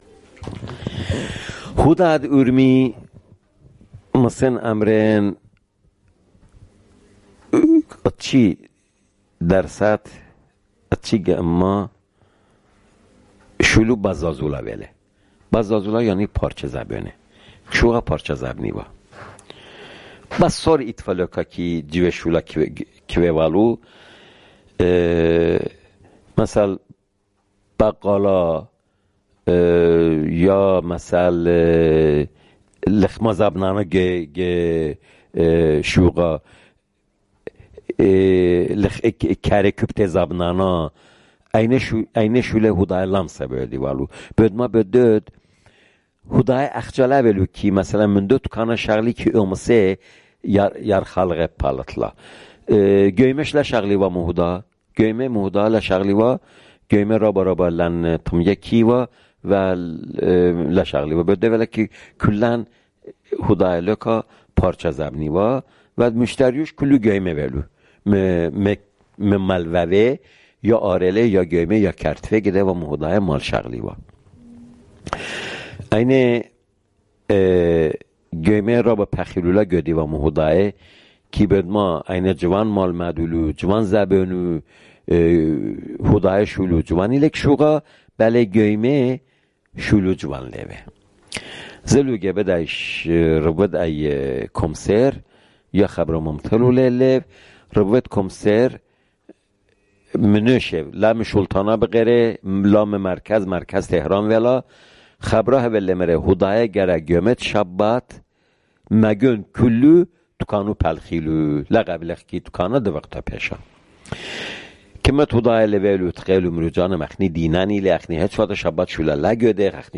Urmi, Jewish: The Professions of the Jews